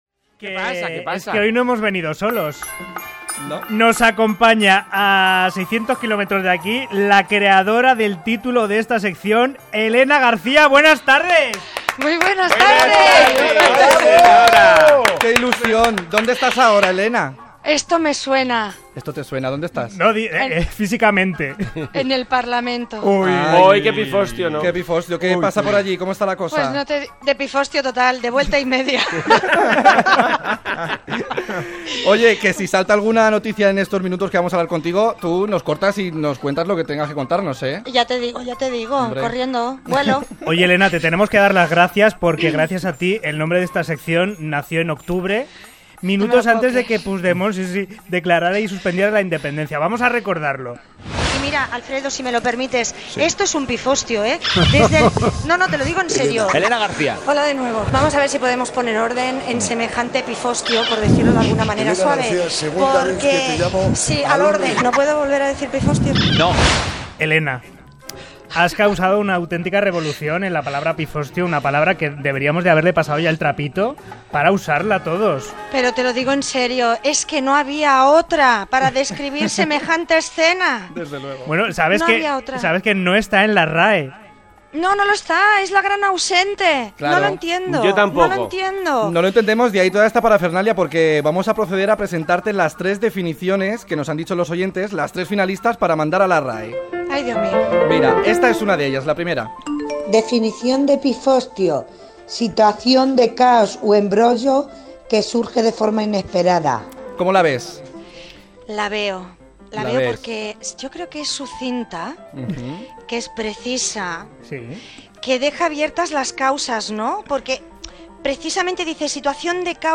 Tres oïdors participen amb les seves definicions de la paraula "pifostio"
Entreteniment